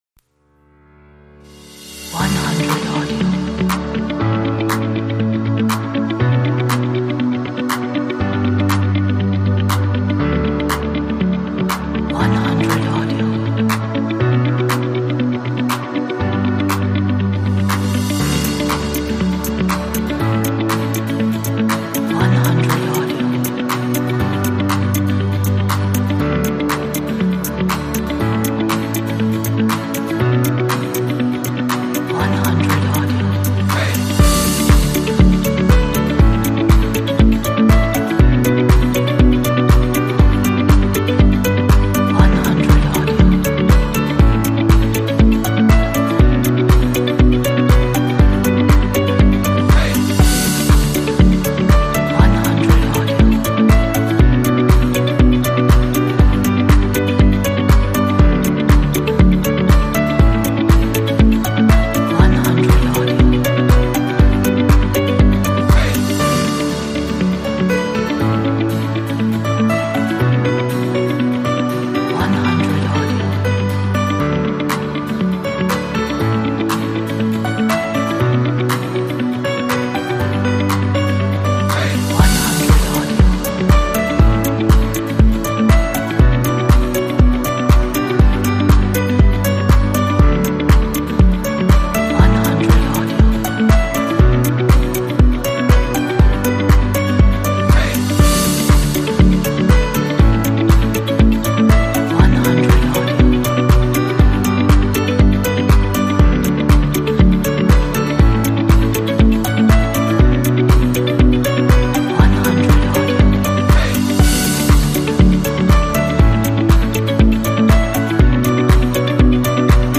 Uplifting Motivational Corporate - Very inspiring!
Motivated motivational upbeat power energy.